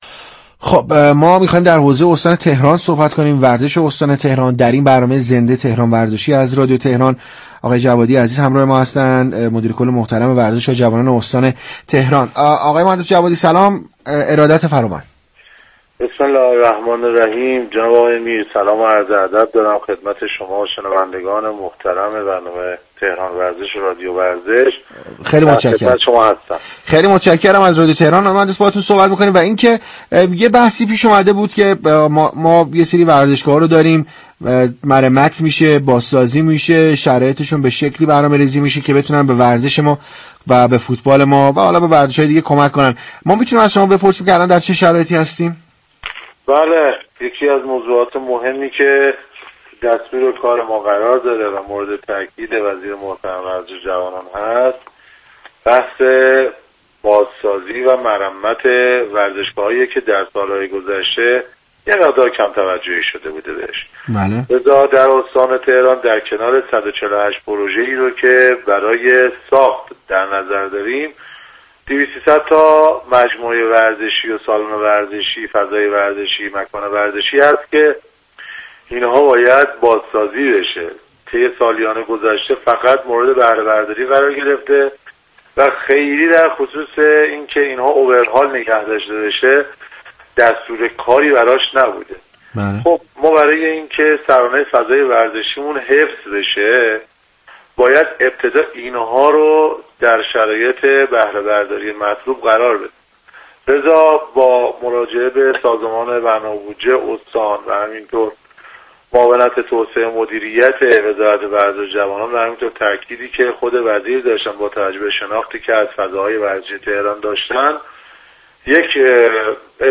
به گزارش پایگاه اطلاع رسانی رادیو تهران، علی جوادی مدیر اداره كل ورزش و جوانان استان تهران در گفت و گو با «تهران وزشی» اظهار داشت: مرمت و بازسازی ورزشگاه‌های تهران مورد تاكید وزیر ورزش و جوانان است و در دستور كار ما قرار دارد.